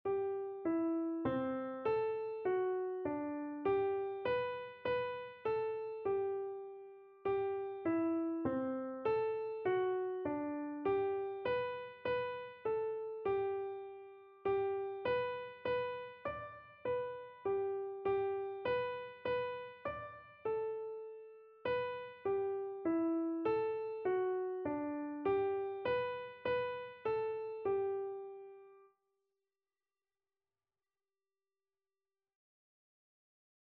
Free Sheet music for Keyboard (Melody and Chords)
G major (Sounding Pitch) (View more G major Music for Keyboard )
3/4 (View more 3/4 Music)
Keyboard  (View more Intermediate Keyboard Music)
Classical (View more Classical Keyboard Music)